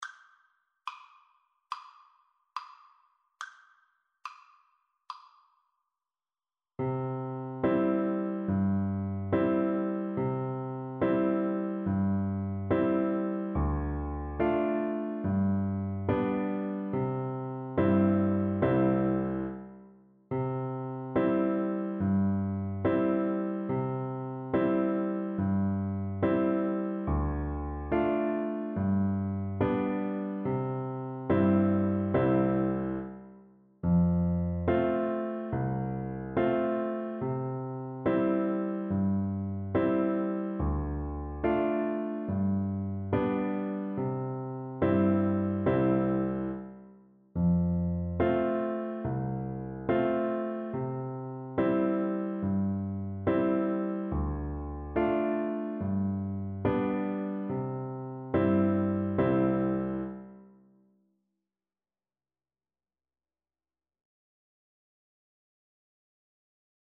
4/4 (View more 4/4 Music)
G5-B6
Traditional (View more Traditional Flute Music)
world (View more world Flute Music)